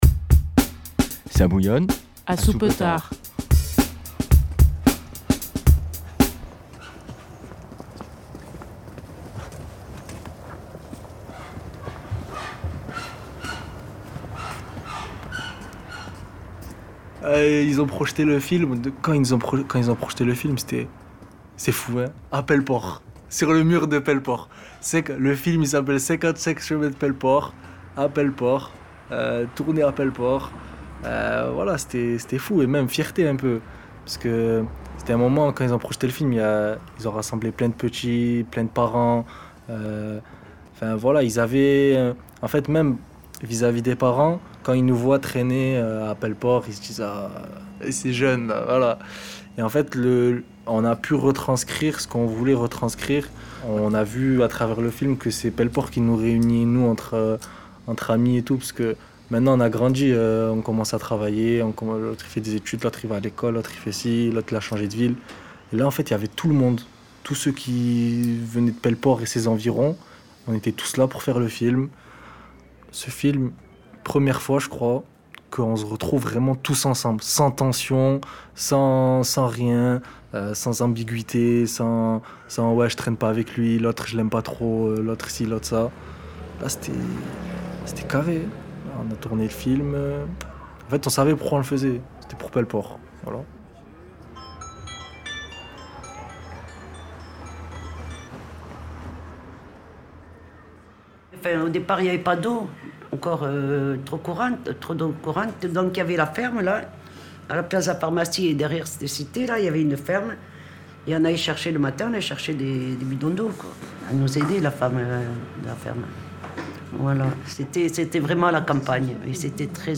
Jeunes et anciens se racontent leurs souvenirs de la cité Pelleport, dans laquelle ils ont vécu. Souvenirs croisés, la nostalgie est là, quand ils savent que les bâtiments vont être détruits. C’est une page de vie qui se tourne, un lieu de vie qui se transforme.